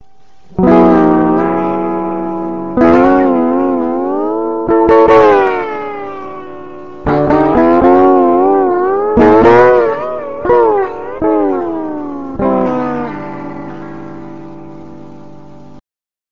Bottleneck-Spiel
bottle.mp3